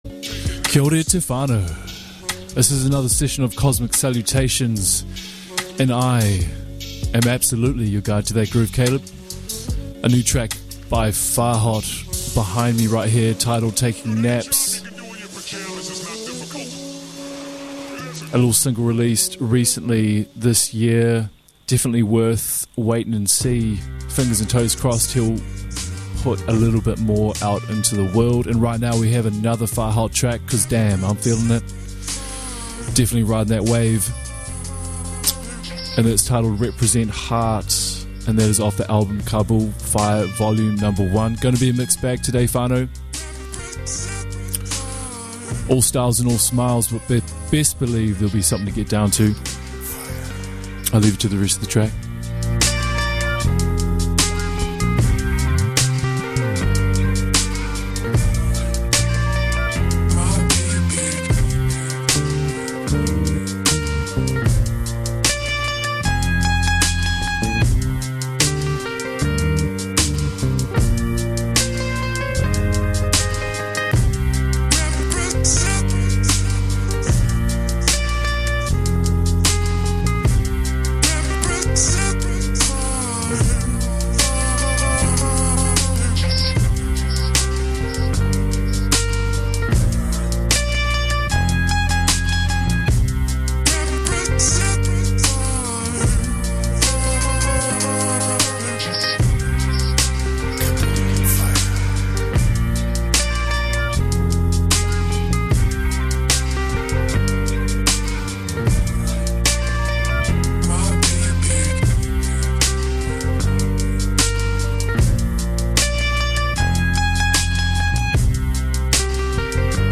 Starting off a little bit slow
smooth, grooves and a little bit of funk